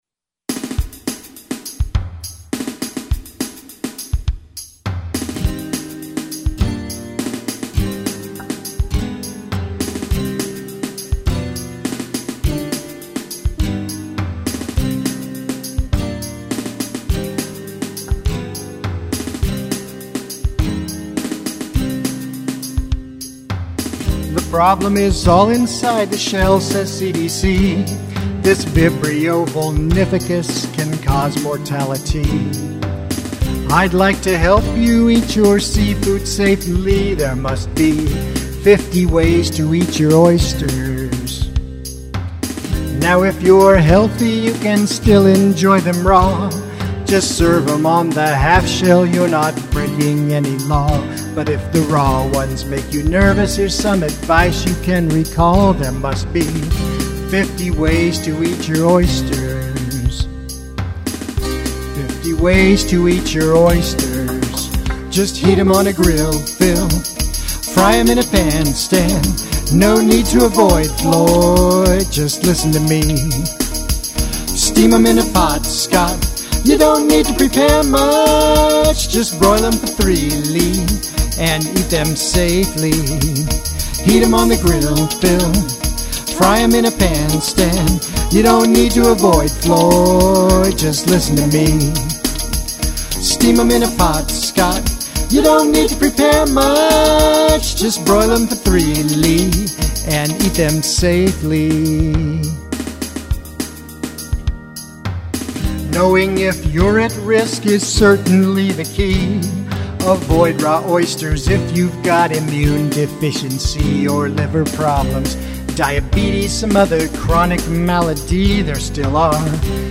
oyster safety song